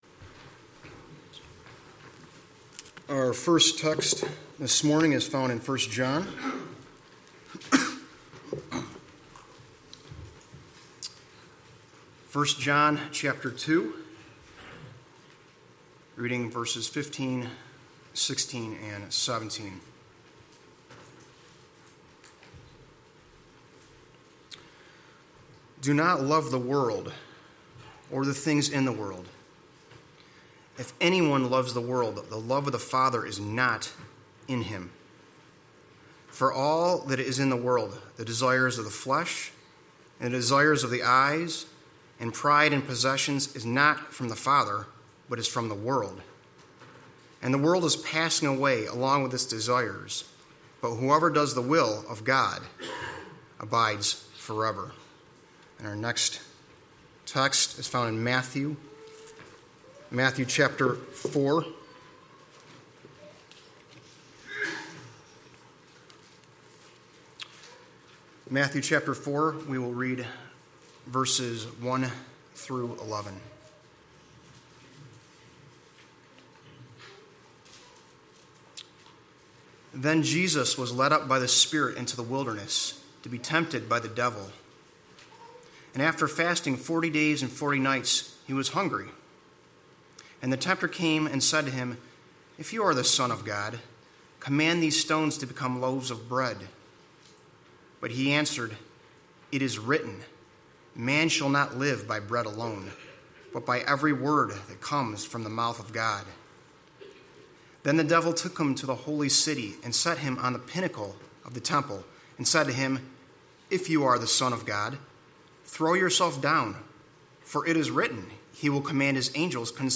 Sermons | Evangelical Church of Fairport